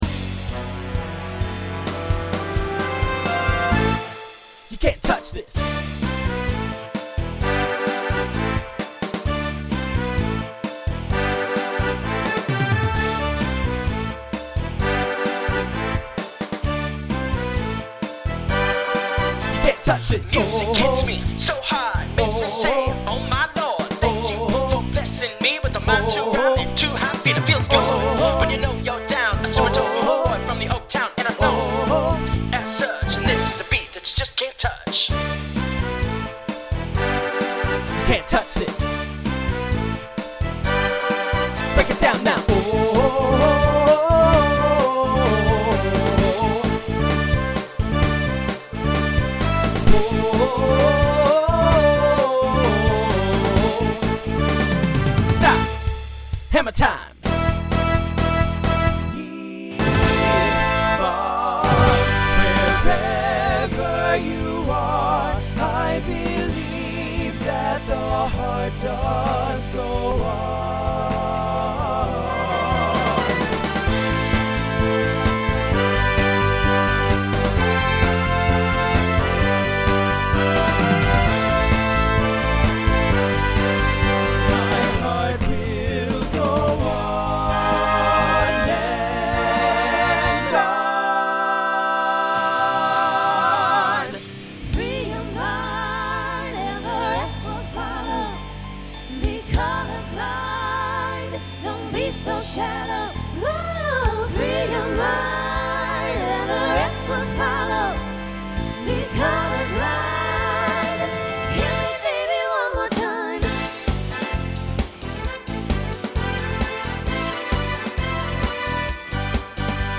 Voicing Mixed Instrumental combo Genre Pop/Dance